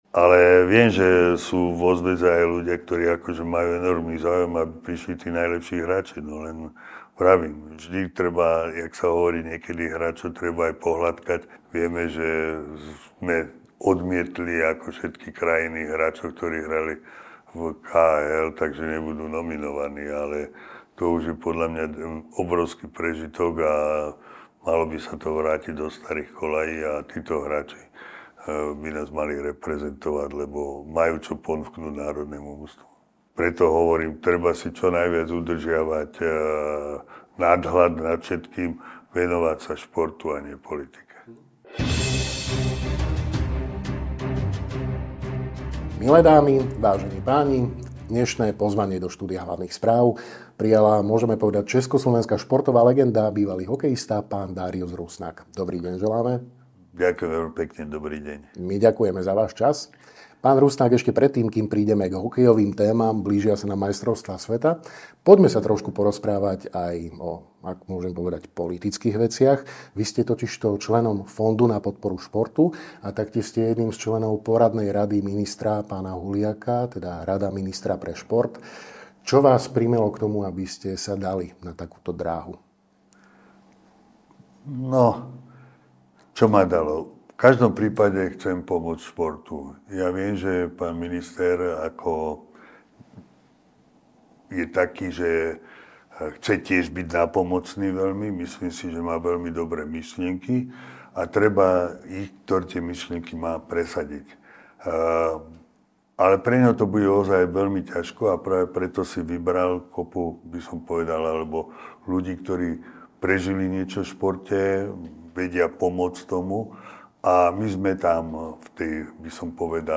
Rozprávali sme sa s hokejovou legendou a členom Fondu na podporu športu, ako aj Rady ministra pre šport, Dáriusom Rusnákom.